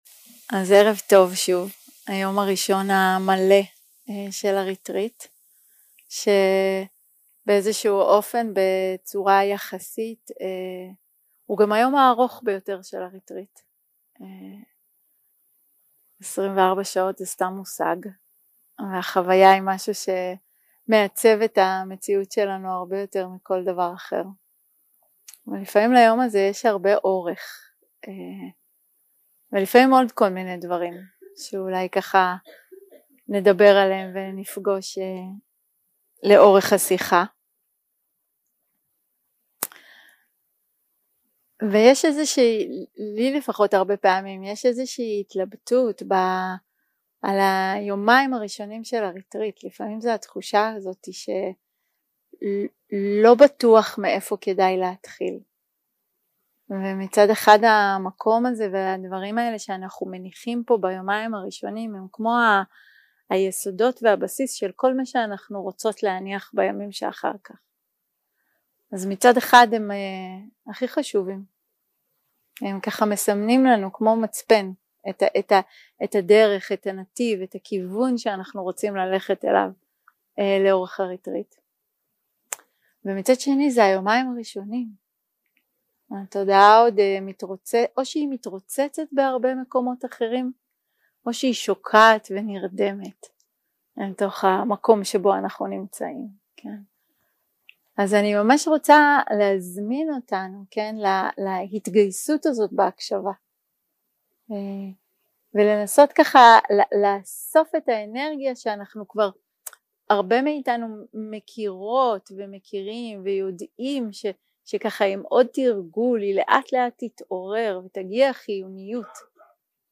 יום 2 – הקלטה 4 – ערב – שיחת דהארמה – החופש שבקבלה
Dharma type: Dharma Talks שפת ההקלטה